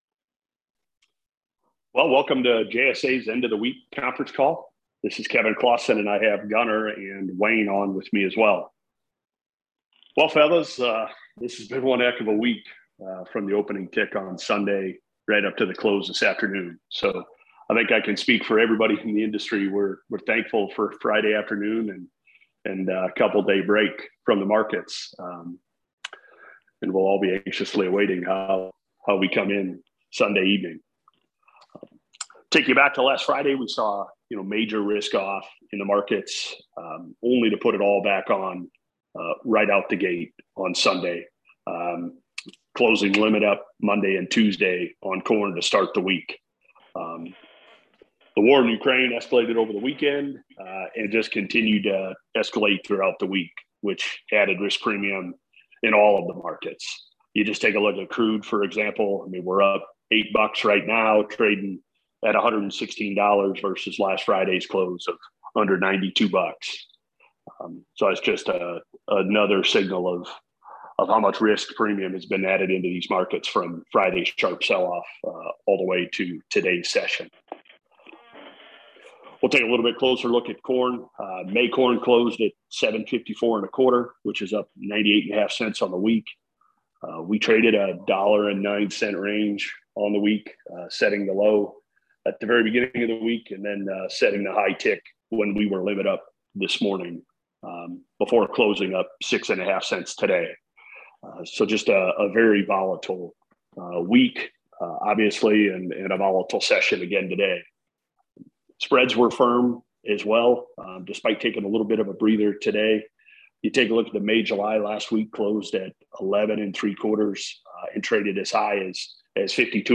JSA Weekly Conference Call 3/4/2022